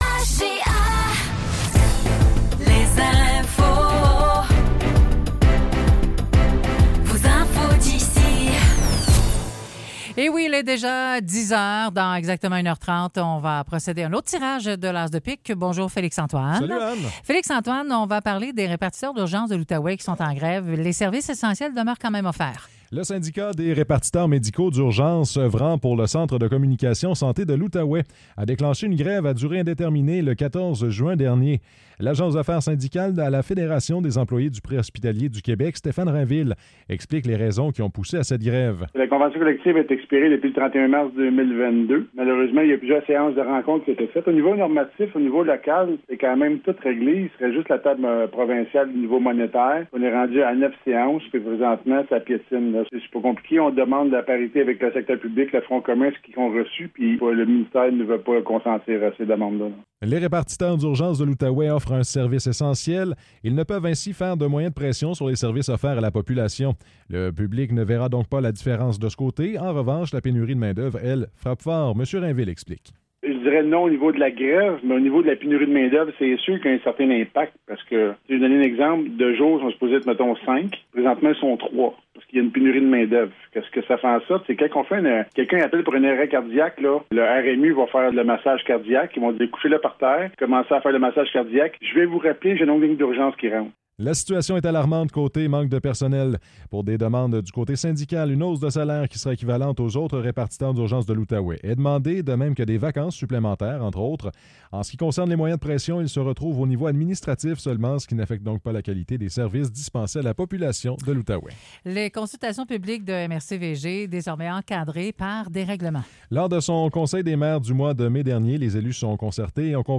Nouvelles locales - 20 juin 2024 - 10 h